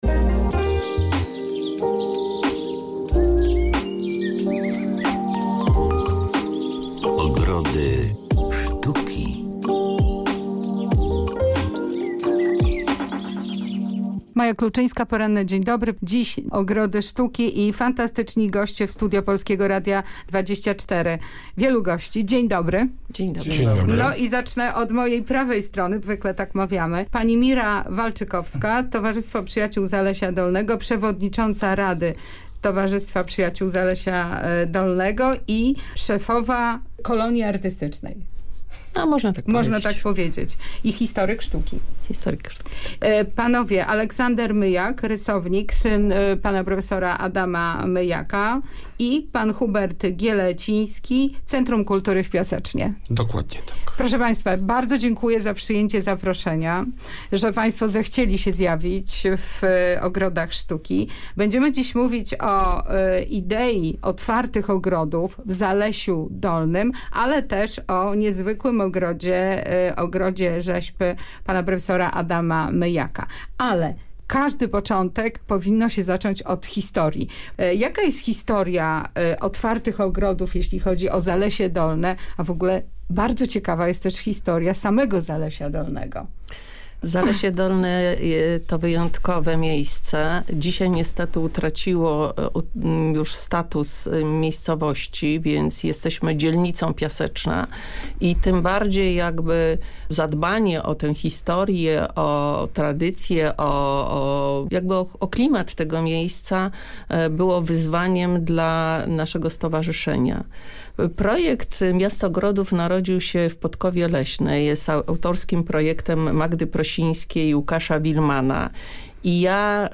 otwarte-ogrody-audycja-zalesie-dolne-miasto-ogrodow-i-artystow.mp3